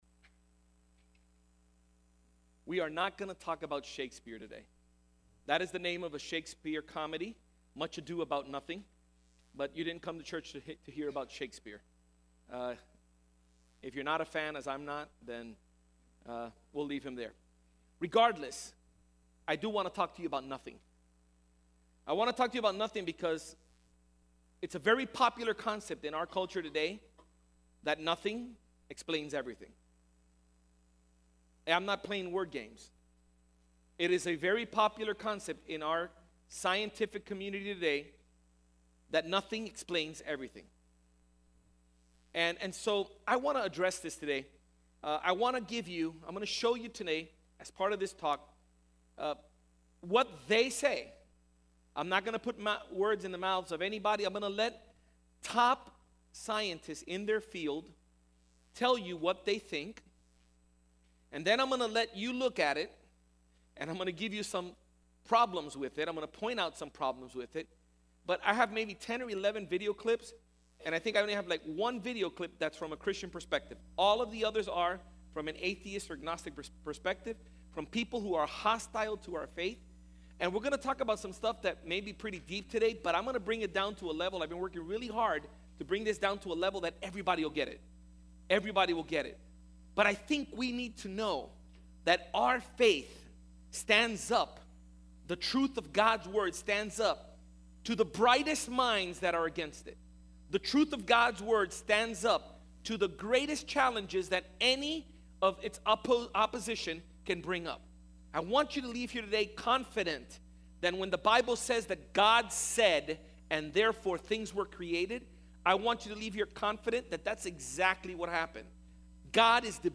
Sermons | Iglesia Centro Evangelico